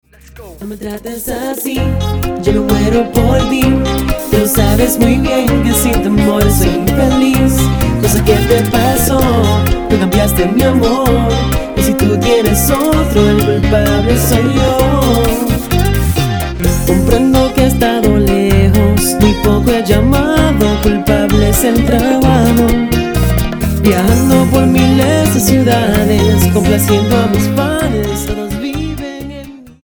Bachata Charts - Februar 2011